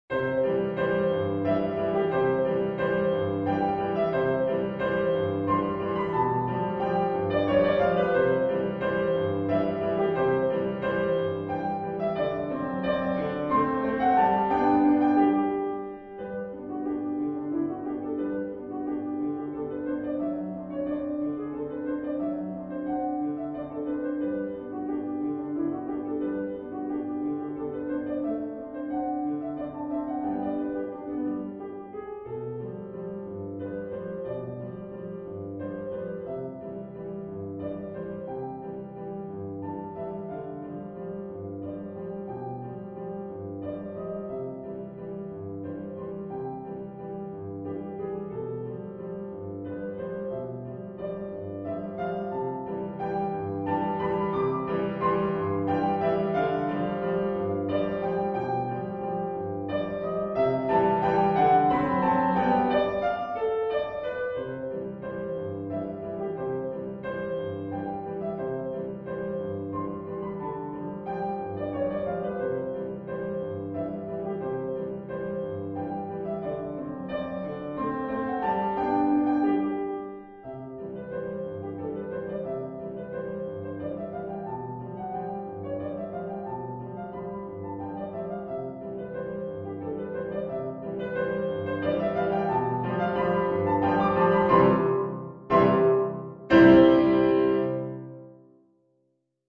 011-waltz.mp3